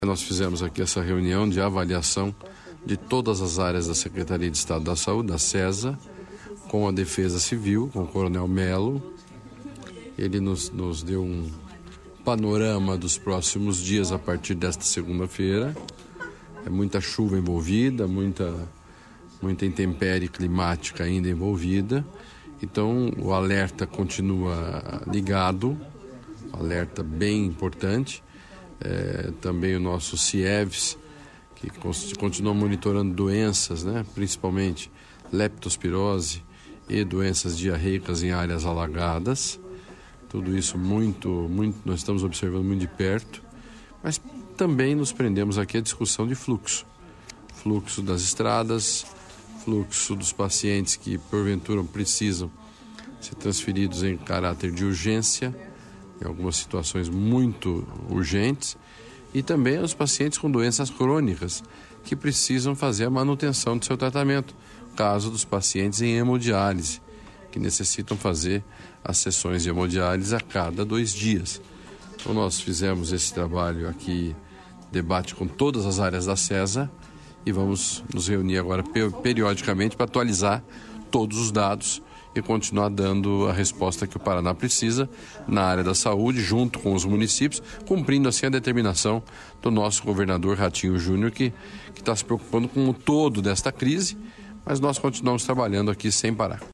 Sonora do secretário da Saúde, Beto Preto, sobre a reunião de gestores da saúde